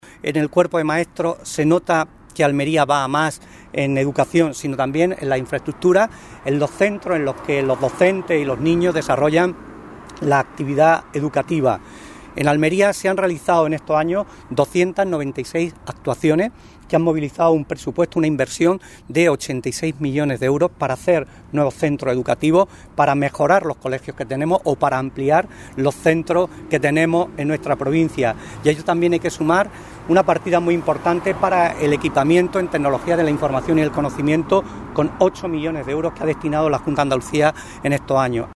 Rueda de prensa del candidato del PSOE de Almería al Parlamento de Andallucía, José Luis Sánchez Teruel